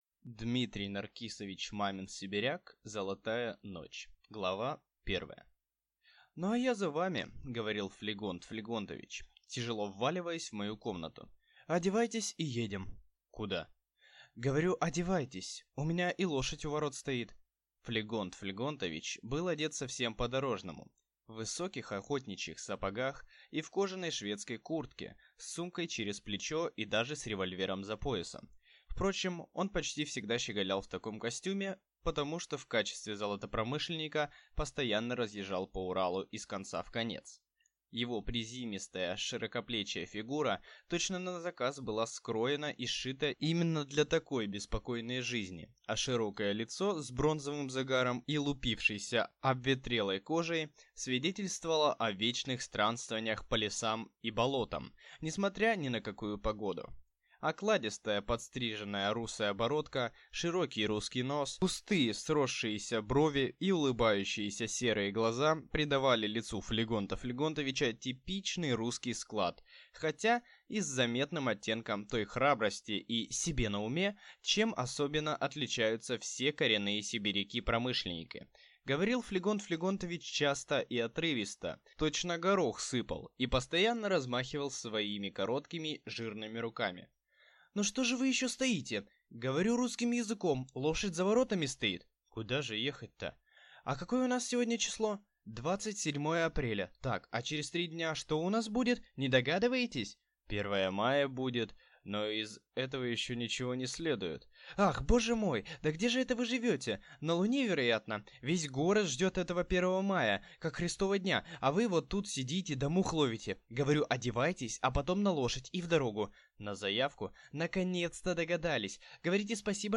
Аудиокнига Золотая ночь | Библиотека аудиокниг